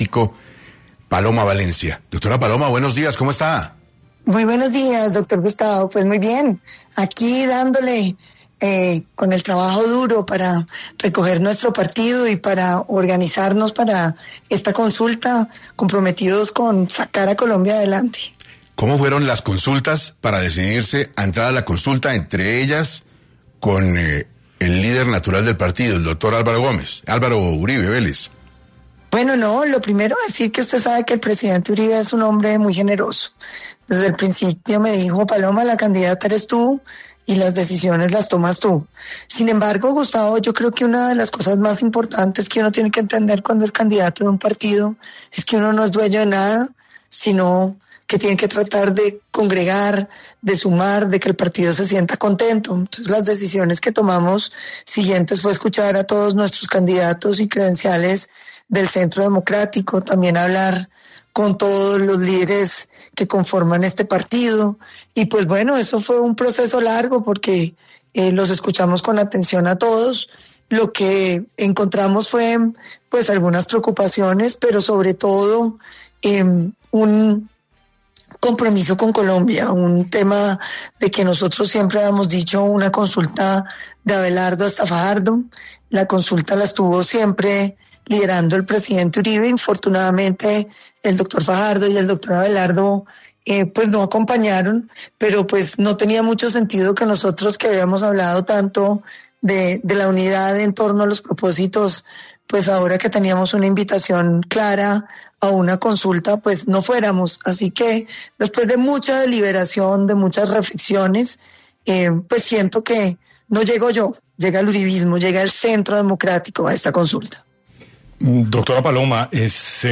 En 6AM de Caracol Radio estuvo Paloma Valencia, candidata presidencial del Centro Democrático, quien habló sobre la consulta y en qué van los diálogos con otros candidatos